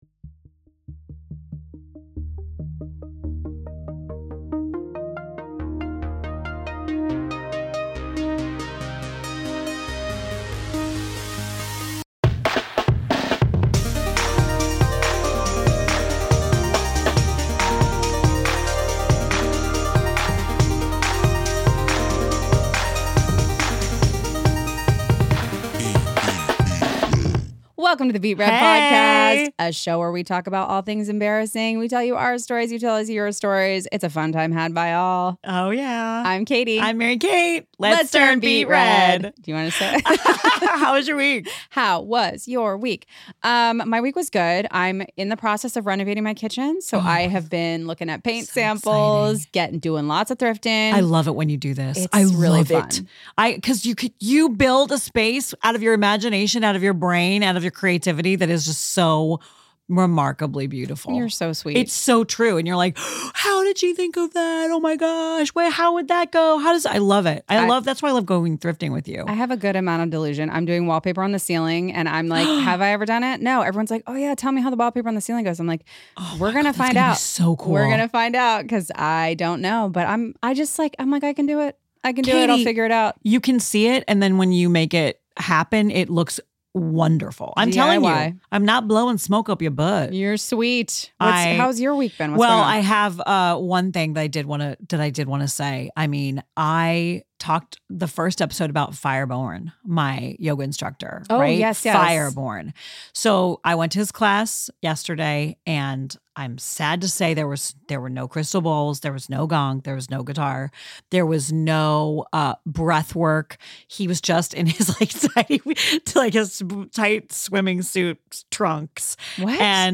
Original theme music